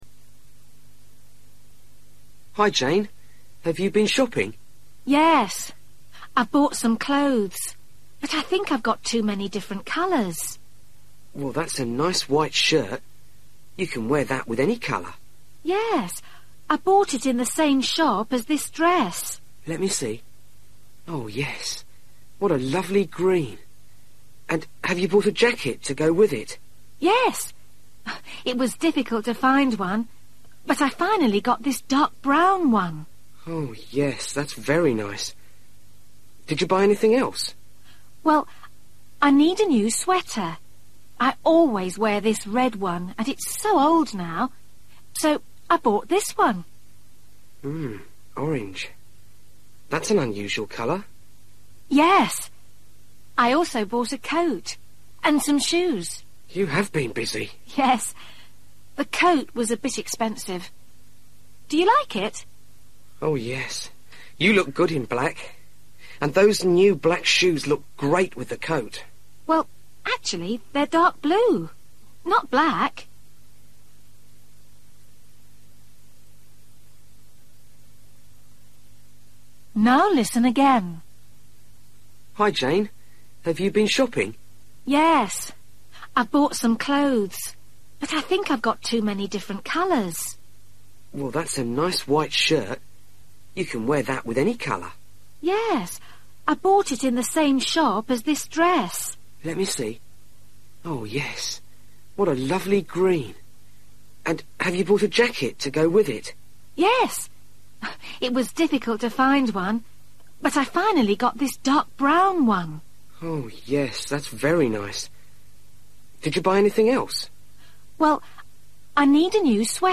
Listen to Jane talking to a friend about some clothes that she has bought for her holiday.